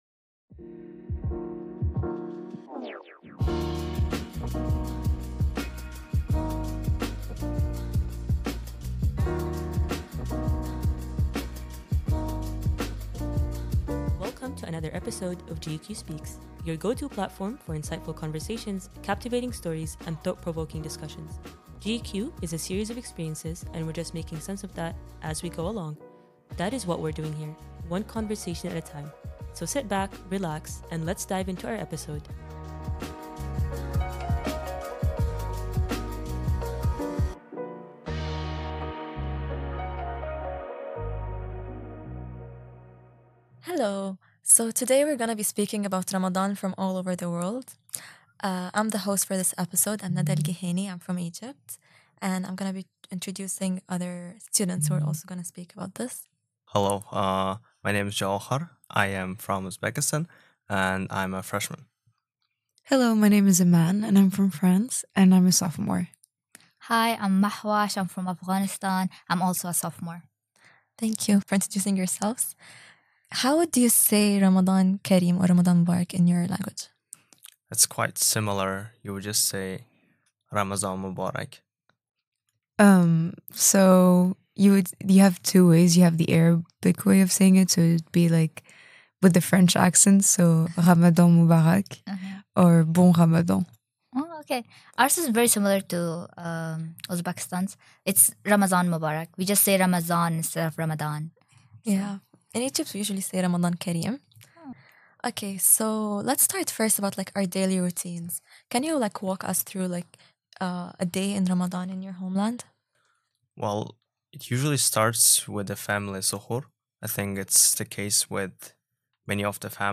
Join us in our conversation about the diverse Ramadan experiences from around the globe, spanning from Uzbekistan to France, with four GU-Q students. During this conversation, we delve deeper into the differences and similarities between each country and its practices during the holy month.